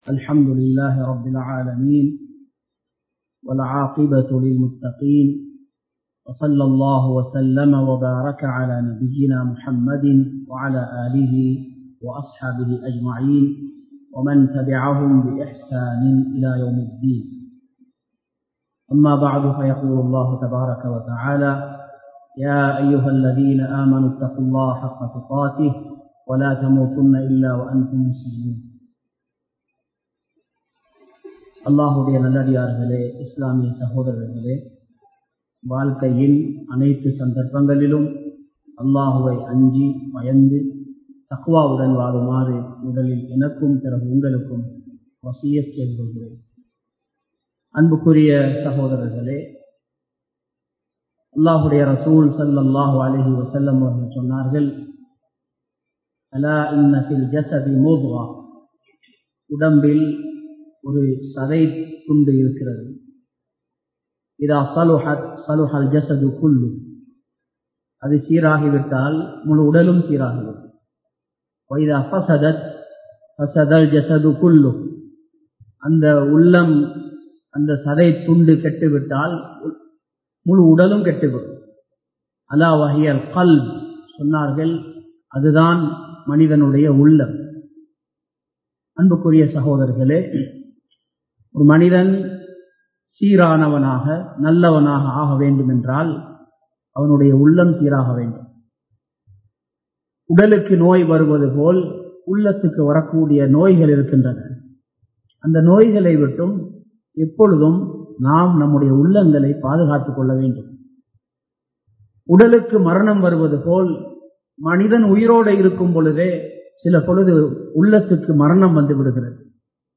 அல்லாஹ்வை அதிகமாக நினைவு கூறுவோம் | Audio Bayans | All Ceylon Muslim Youth Community | Addalaichenai
Muhideen (Markaz) Jumua Masjith